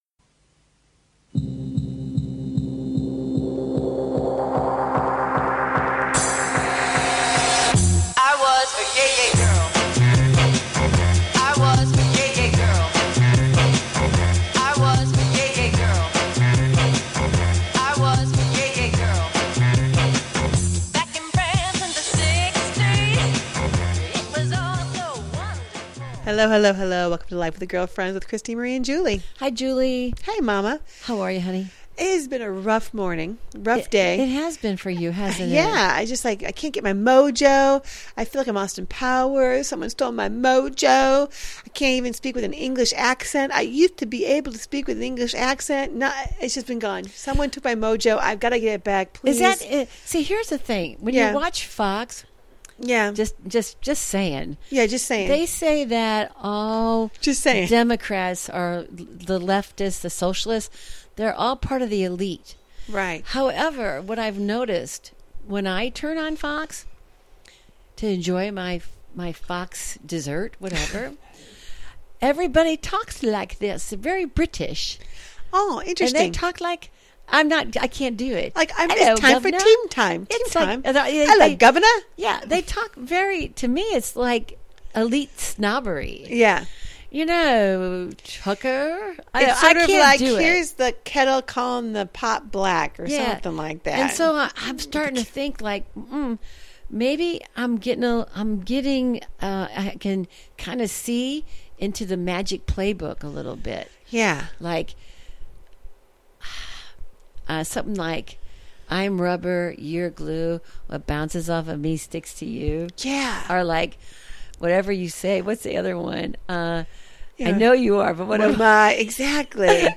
They welcome a wide range of guest to their den for some juicy conversation.